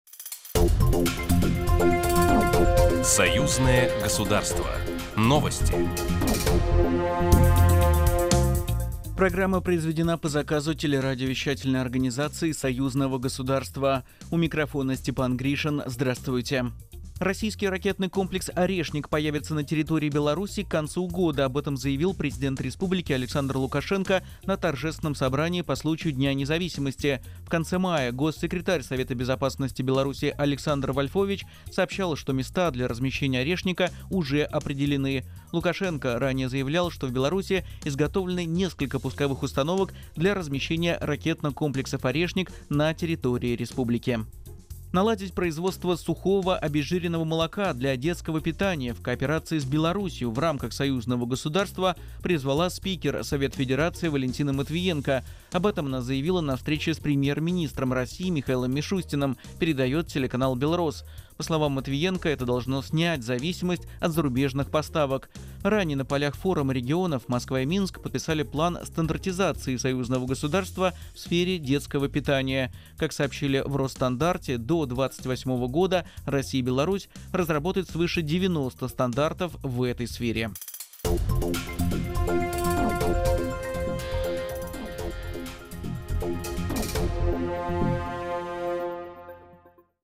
Ежедневная информационная программа, посвященная событиям Союзного государства. Программа ежедневно и оперативно знакомит радиослушателей с наиболее важными и значимыми событиями двух государств России и Беларуси. Комментарии экспертов, самое важное и актуальное.